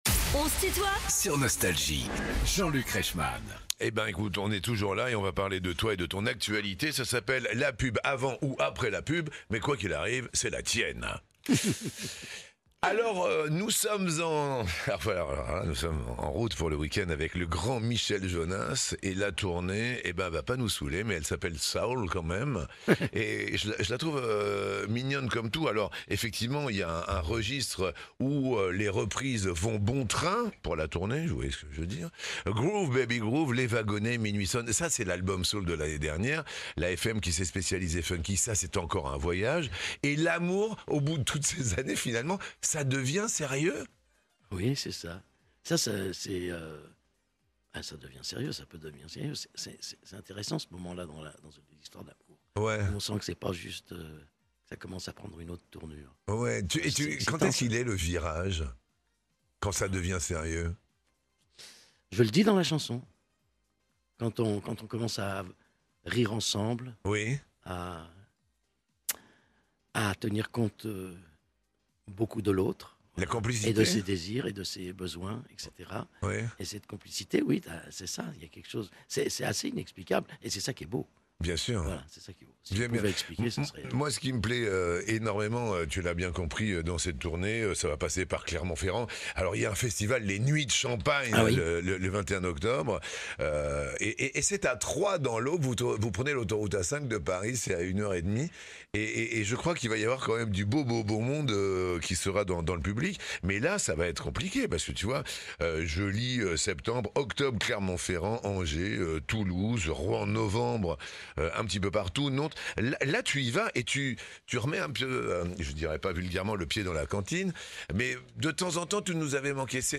Michel Jonasz est l'invité de "On se tutoie ?..." avec Jean-Luc Reichmann (Partie 2) ~ Les interviews Podcast